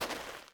SAND.2.wav